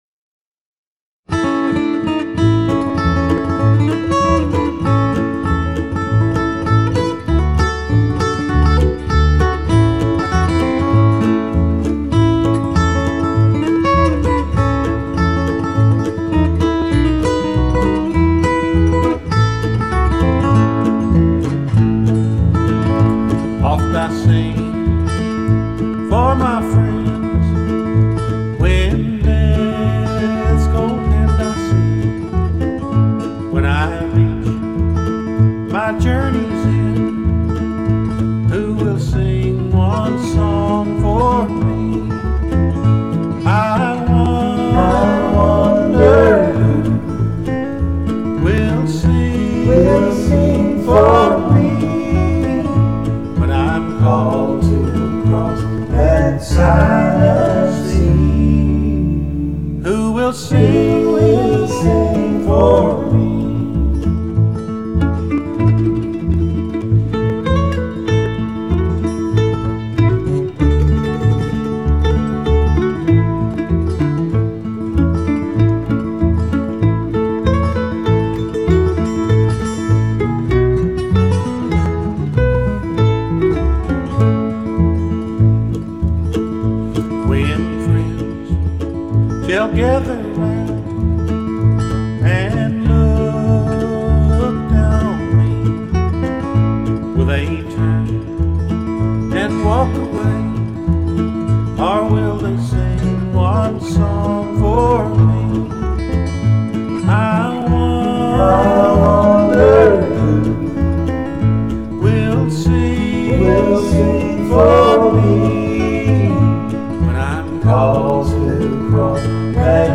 all gospel
mandolin, vocals